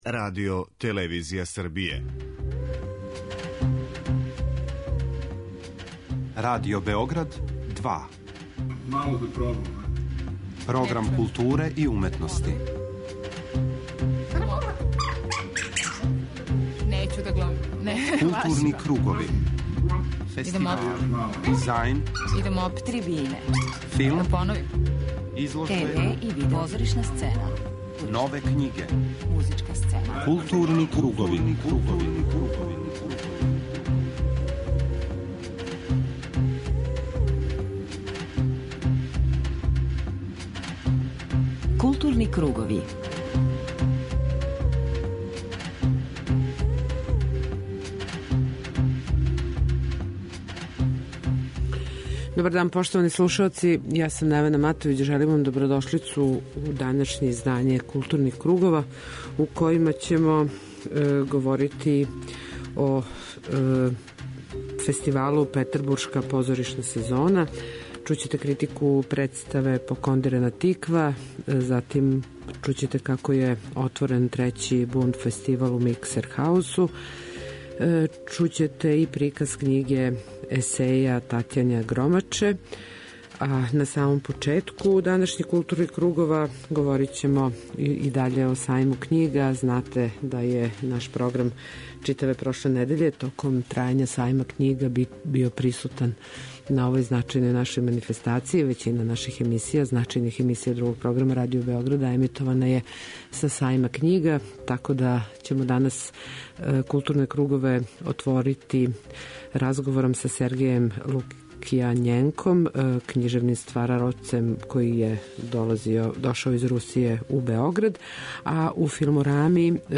преузми : 40.97 MB Културни кругови Autor: Група аутора Централна културно-уметничка емисија Радио Београда 2.